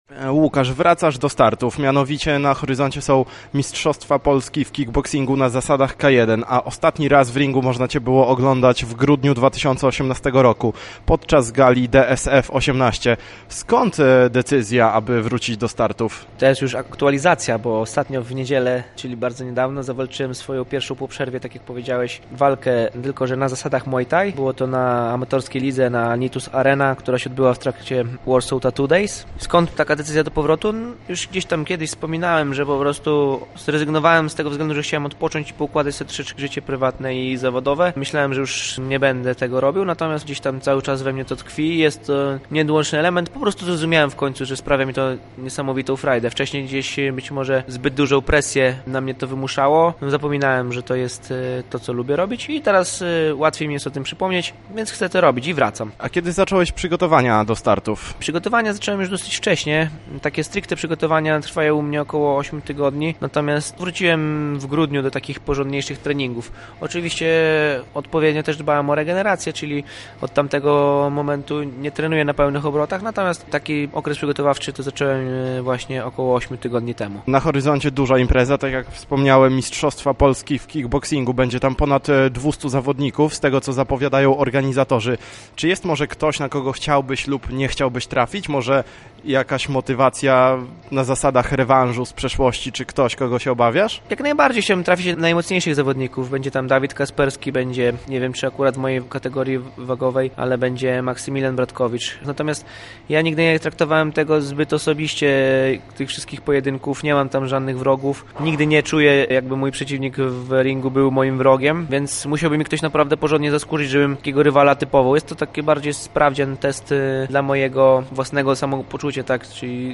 Między innymi o swojej przerwie od startów opowiedział w rozmowie sam zawodnik.